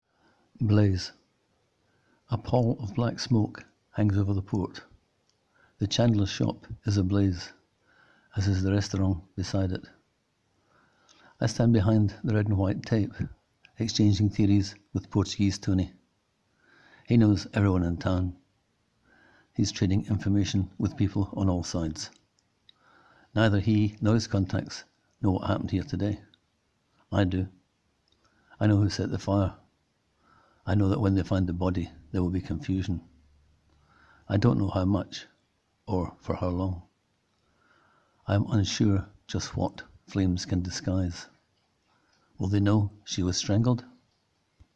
Click here to hear the writer read his words:
The calm, unconcerned telling of this gives it just the right atmosphere.